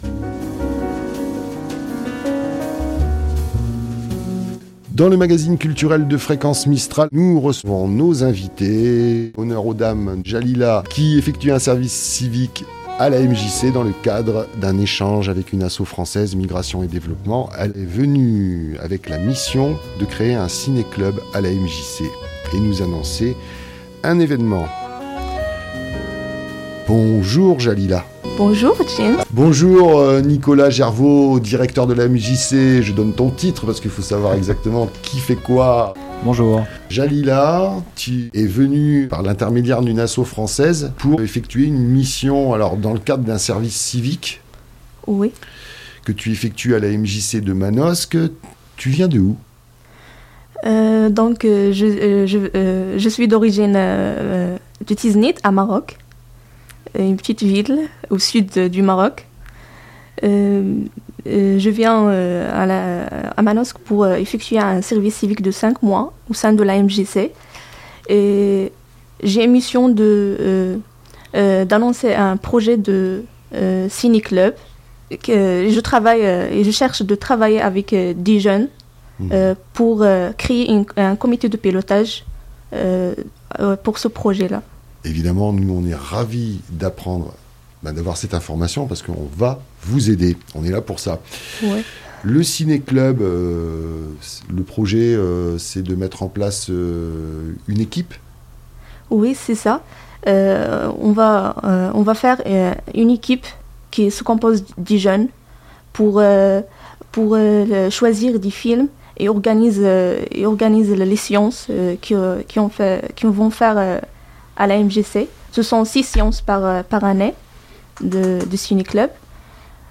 Nous vous proposons de les écouter dans une entrevue